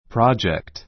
prɑ́dʒekt プ ラ ヂェ クト ｜ prɔ́dʒekt プ ロ ヂェ クト